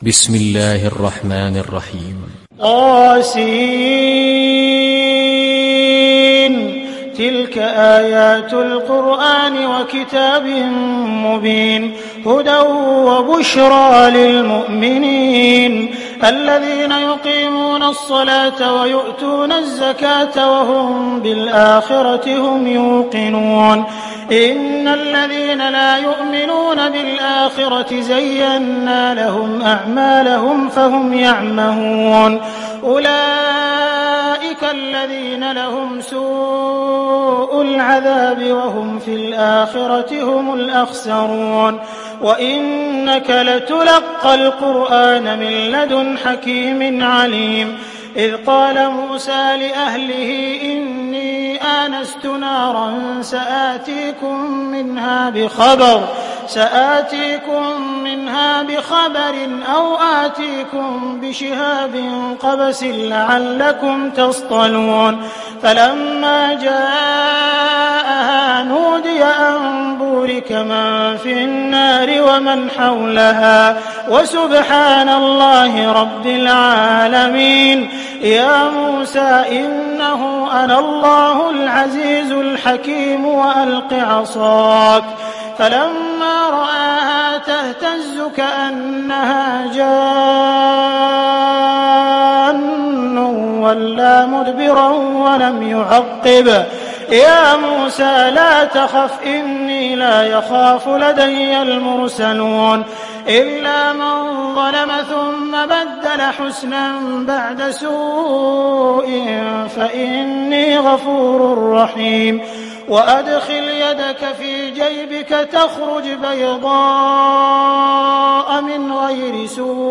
Neml Suresi İndir mp3 Abdul Rahman Al Sudais Riwayat Hafs an Asim, Kurani indirin ve mp3 tam doğrudan bağlantılar dinle